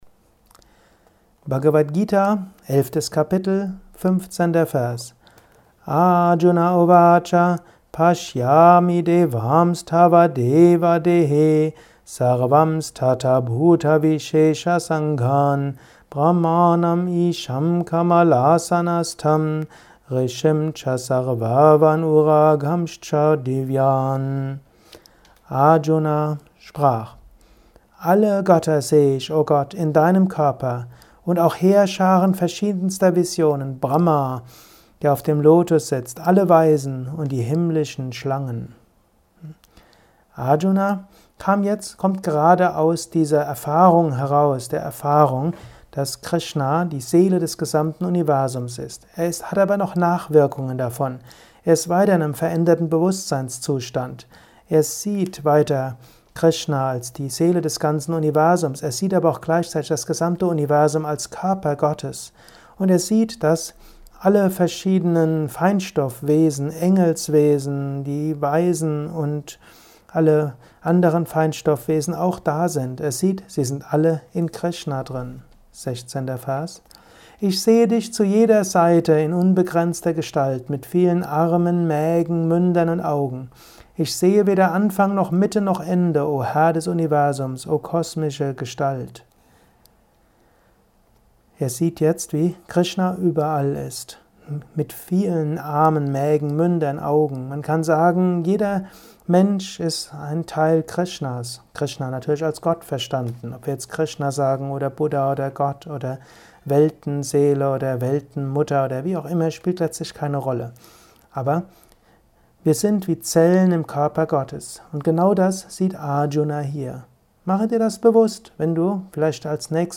Dies ist ein kurzer Kommentar als Inspiration für den heutigen Tag